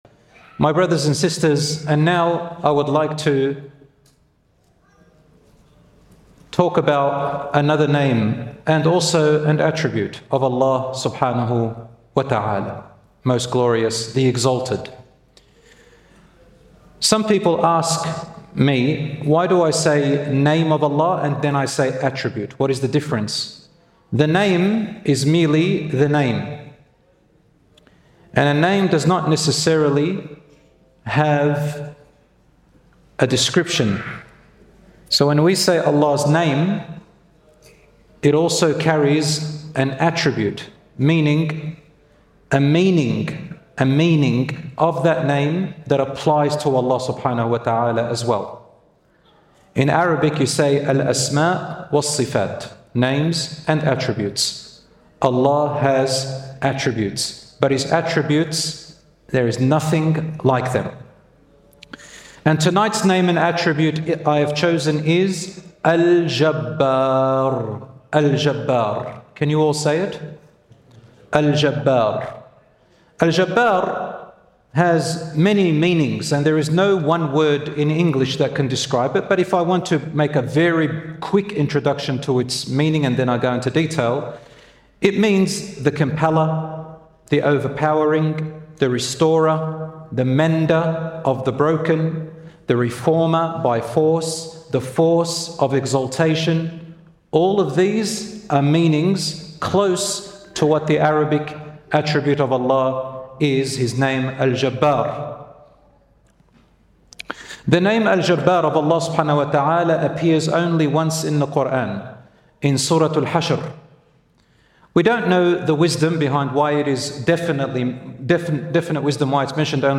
In this lecture, we explore the deep and multifaceted meanings of Allah’s name Al-Jabbār, highlighting His absolute power, ability to heal the broken, and exalted status above all creation.